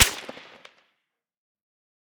med_crack_03.ogg